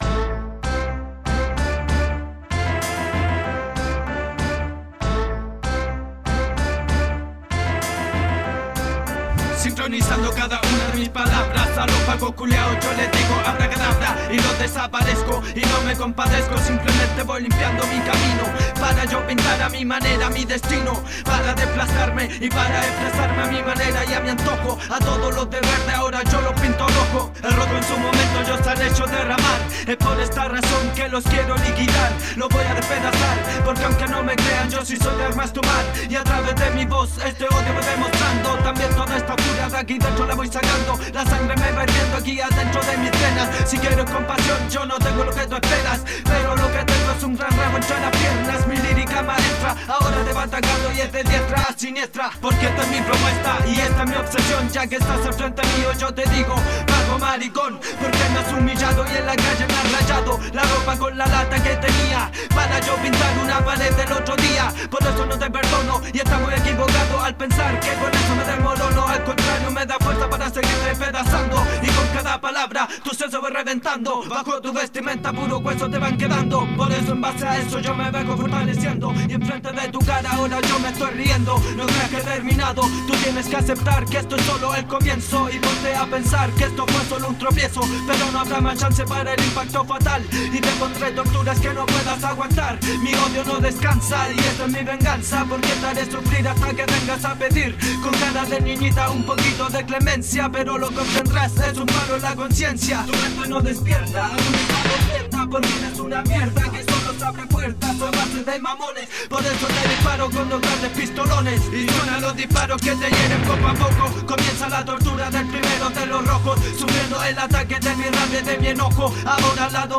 Este es un demo